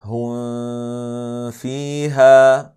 3. Att Dölja det vid bokstäverna Wāw (و) eller Fā’ (ف), som i:
Det ska uttalas på följande sätt: